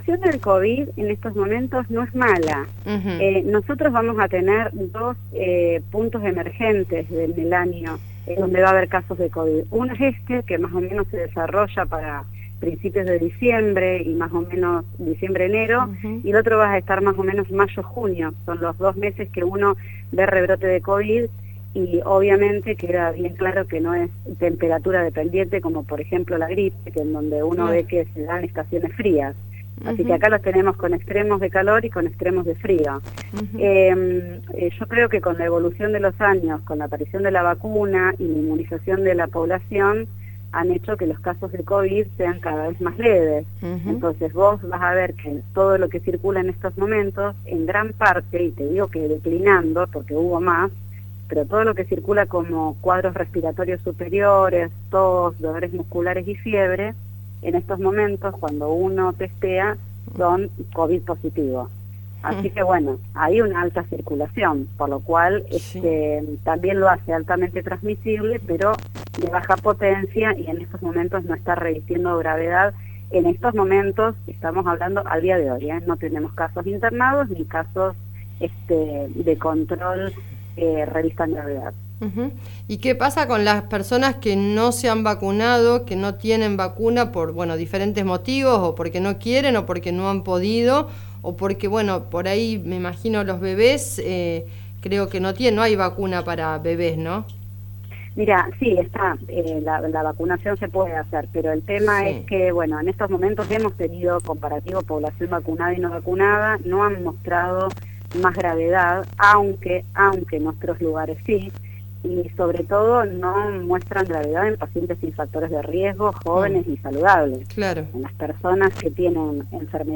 pasó por los micrófonos de Radio Del Sur FM 90.5